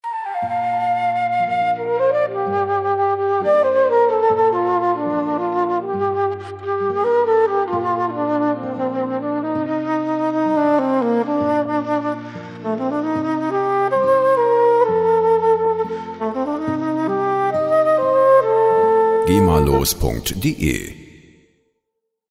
Instrument: Querflöte
Tempo: 100 bpm